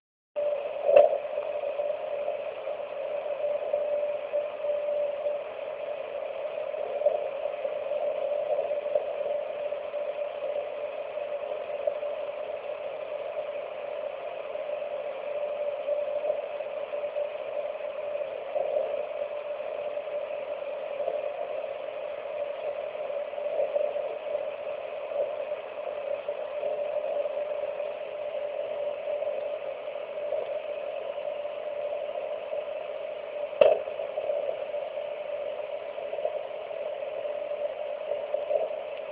Maximum slyšitelnosti a čitelnosti bylo mezi 7.50 až 8.00 SEČ.
Jak se ukazuje, základním předpokladem pro příjem těchto signálů je "tiché" místo.